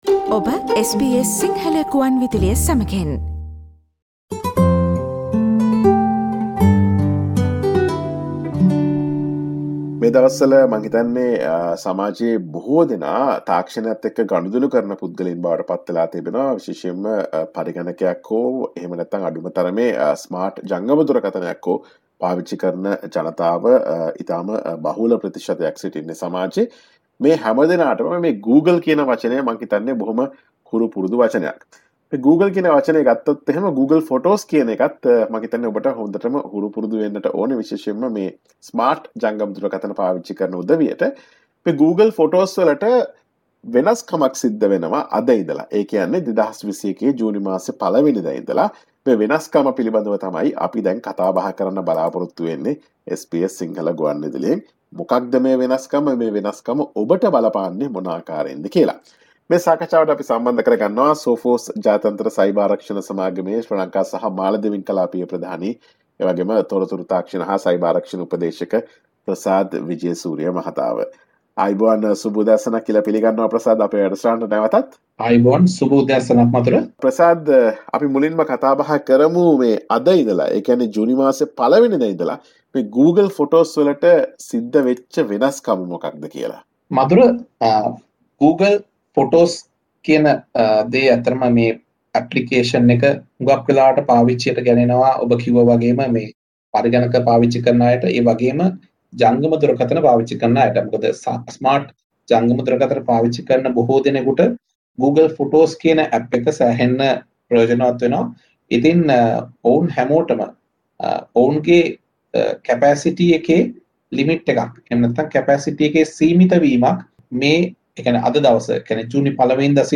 SBS Sinhalese discussion on the latest restrictions on Google Photos from 1 June 2021